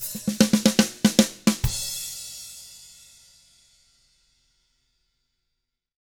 146ROCK E1-R.wav